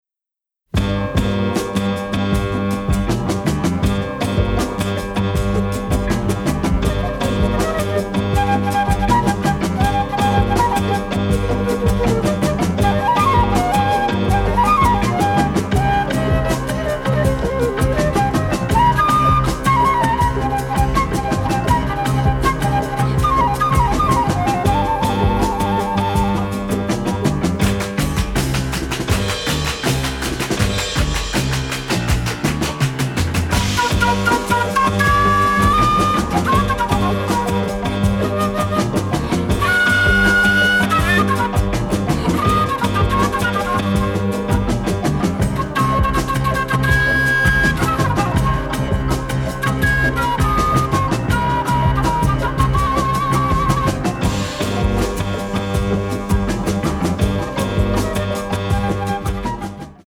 ethnic-flavored eclectic underscoring